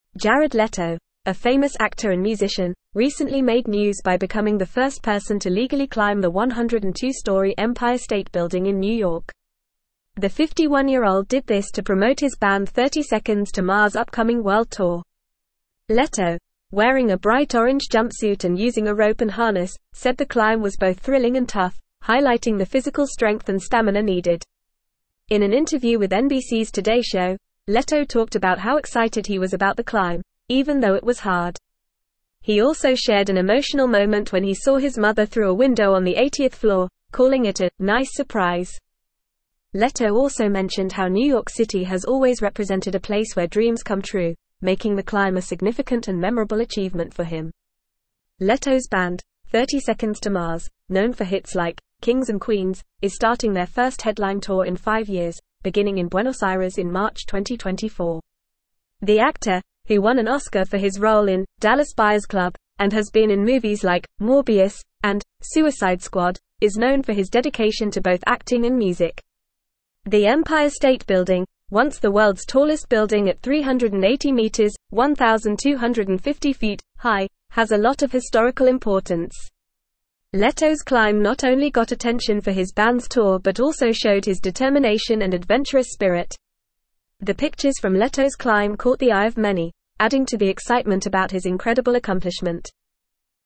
Fast
English-Newsroom-Upper-Intermediate-FAST-Reading-Jared-Leto-Scales-Empire-State-Building-for-Band.mp3